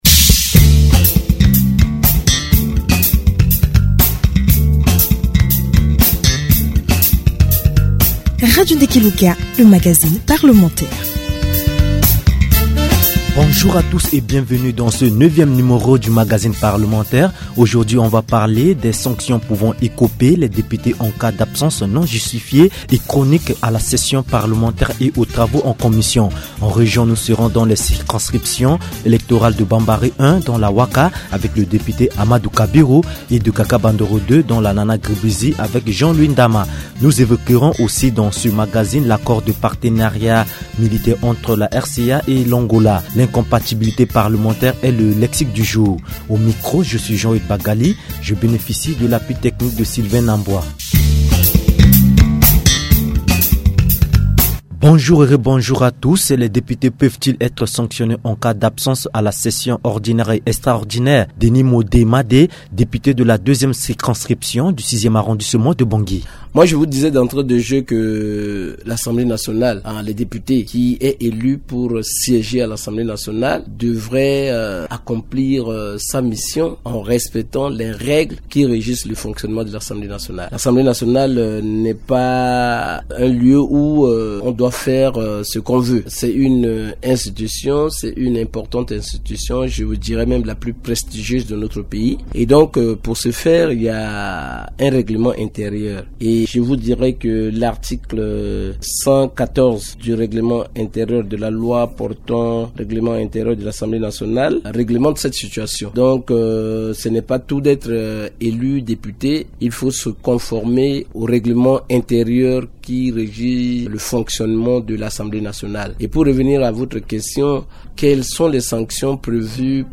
Cependant certains élus pour une quelconque raison manque à l’appel lors des sessions en plénière ainsi que dans leur commission respective. Dans cette émission les députés évoquent les dispositions pertinentes de la loi qui prévoient les régimes de sanction que peut écoper un élu.